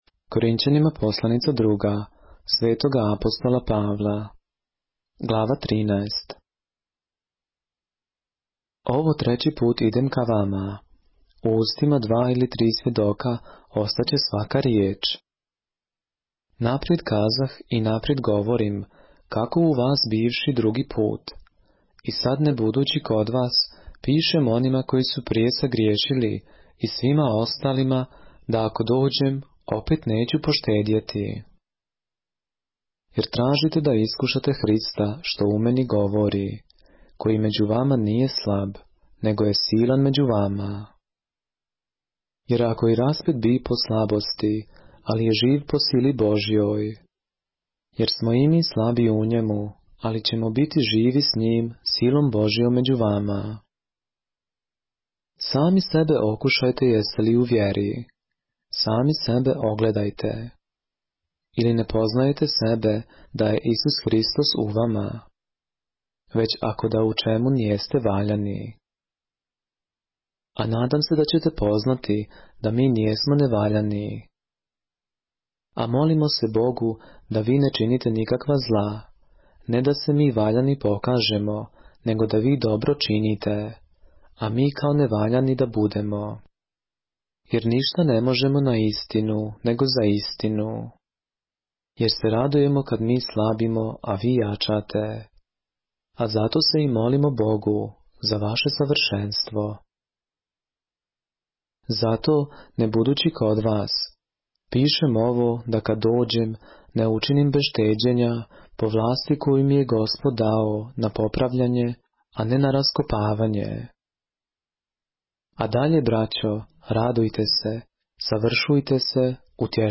поглавље српске Библије - са аудио нарације - 2 Corinthians, chapter 13 of the Holy Bible in the Serbian language